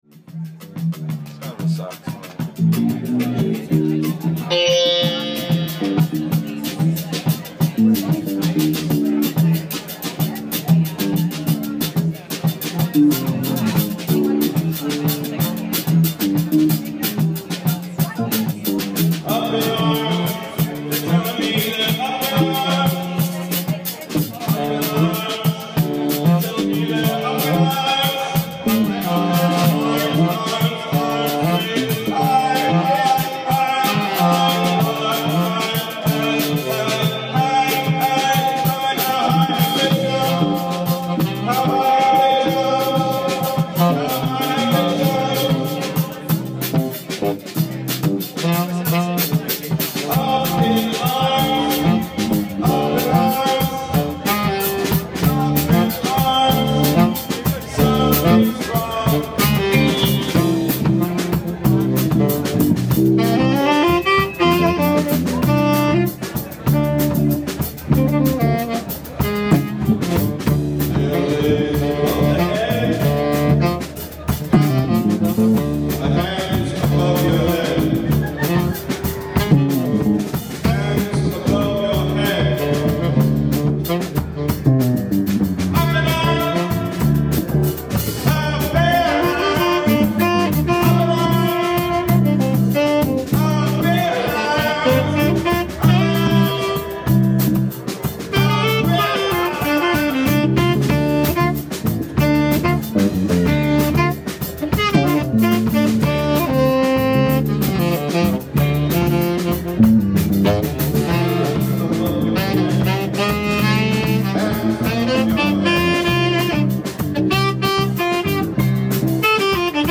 OHR Jaus Gallery, Sep 16th, 2016
ALL MUSIC IS IMPROVISED ON SITE